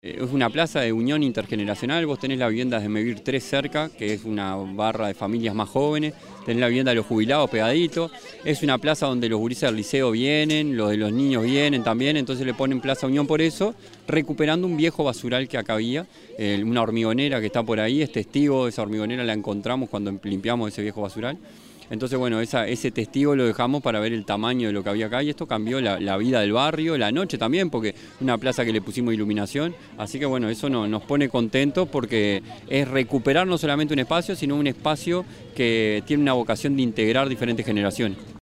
El Alcalde de Los Cerrillos, Prof. Rodrigo Roncio, en la inauguración del nuevo espacio público Unión en Los Cerrillos
audio_rodrigo_roncio_0.mp3